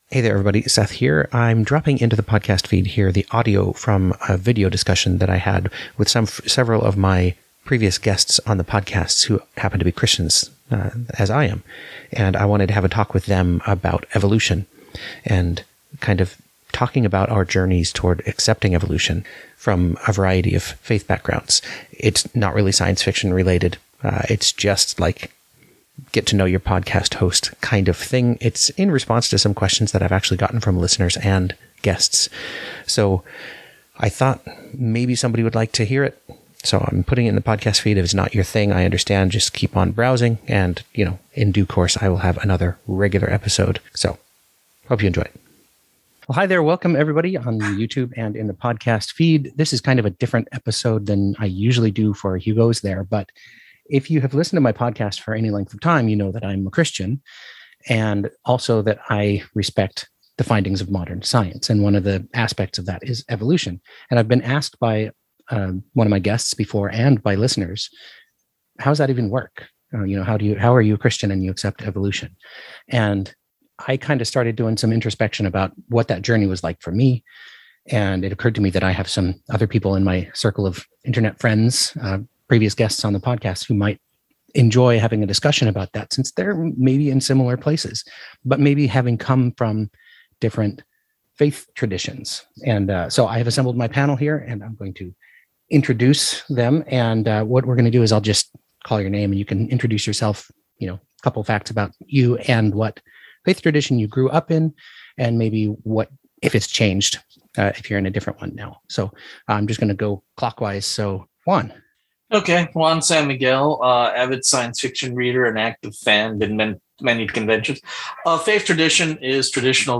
Bonus: Discussion With Christians Who Accept Evolution